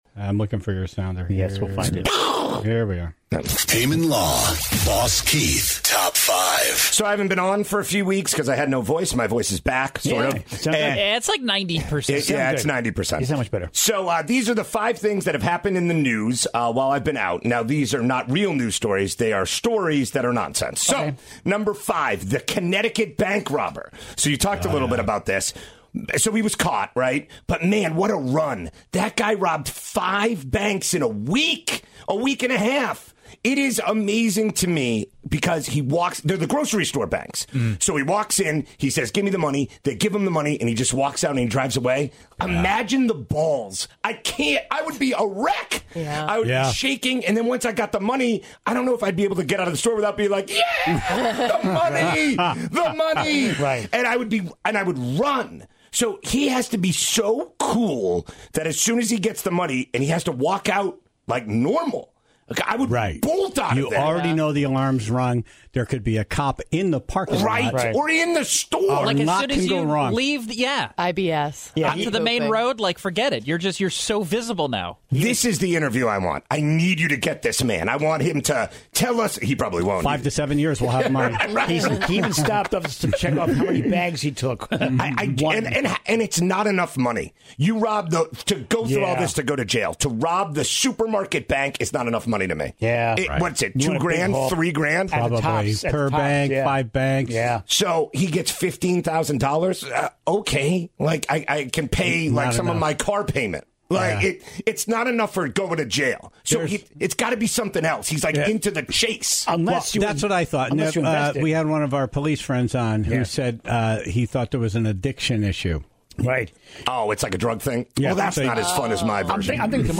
back in studio for his Top 5 list, since his voice has finally recovered from a weekend of yelling.